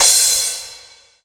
Chart Cymbal 02.wav